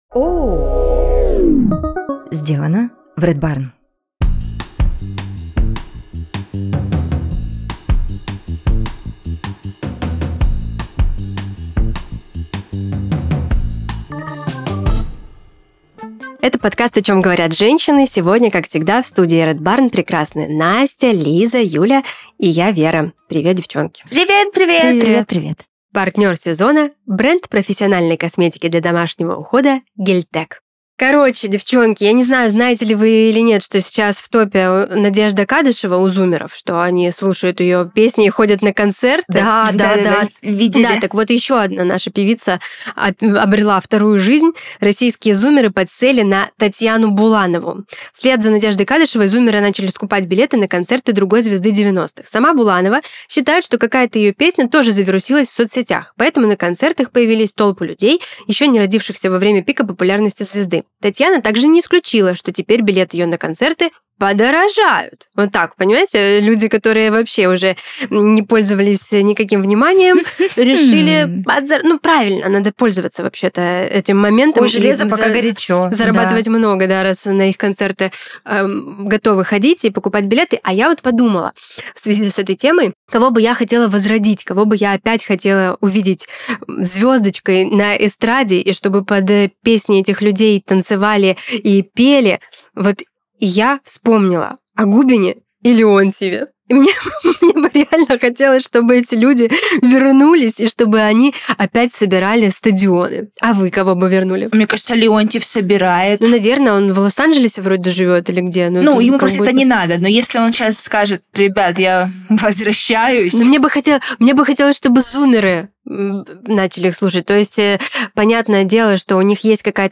Четыре ведущие, четыре взгляда на мир, жизнь и отношения, четыре разгона обо всем, что волнует, радует или раздражает женщин. Каждый выпуск обсуждаем темы от отношений до карьеры без стереотипов, предубеждений и стеснения.